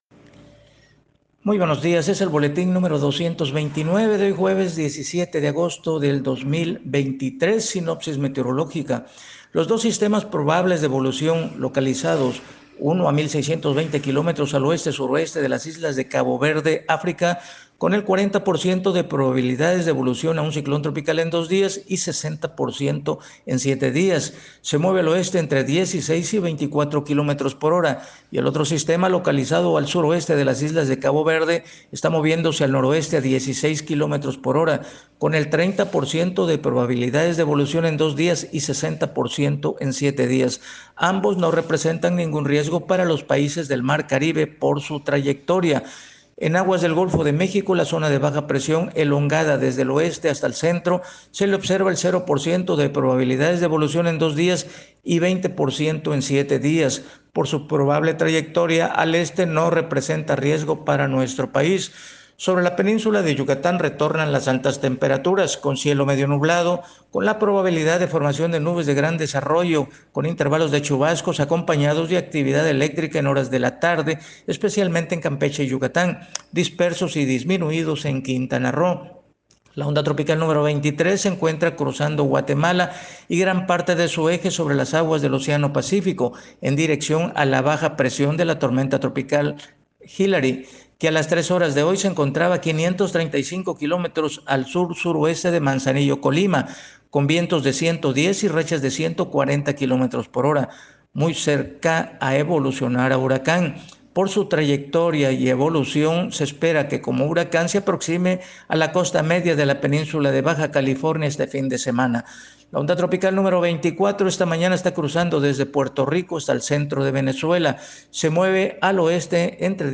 Reporte meteorológico 17 agosto 2023